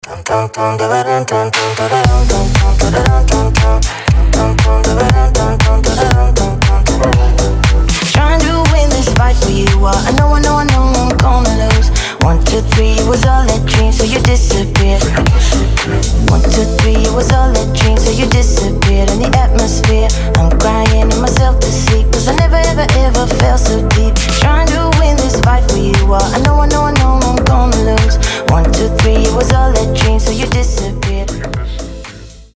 • Качество: 320, Stereo
мужской голос
веселые
Cover
ремиксы
slap house
Отрывок из польки Евы на современный лад